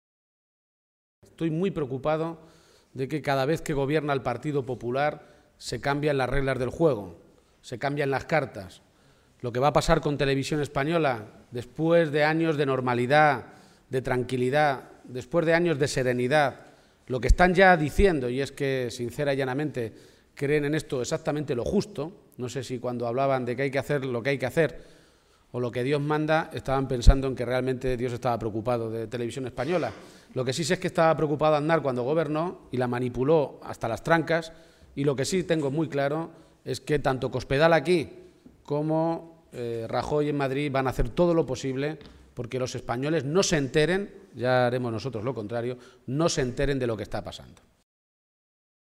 García-Page hacía estas manifestaciones en una comparecencia ante los medios de comunicación, minutos antes de iniciar un encuentro con alcaldes y concejales de La Mancha en la localidad de Campo de Criptana.
Cortes de audio de la rueda de prensa